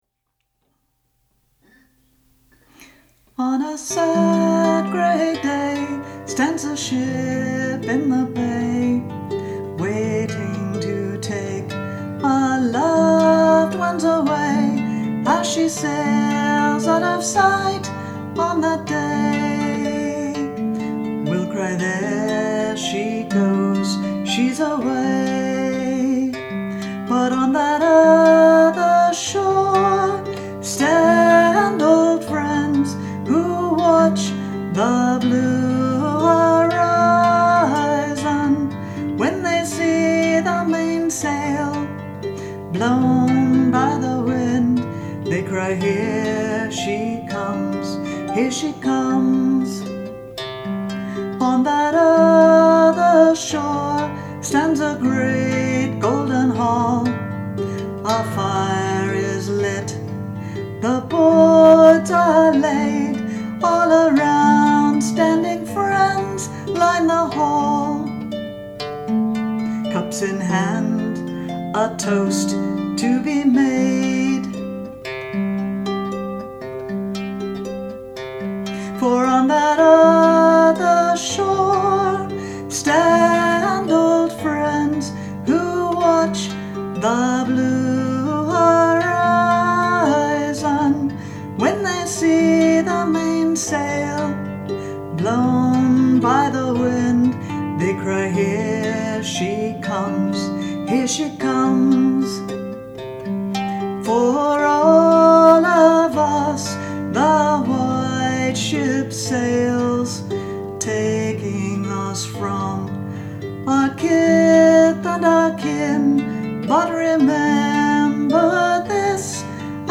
• MP3 of me singing with the harp, but you can hear tears in my voice, so it is not as good for learning the tune.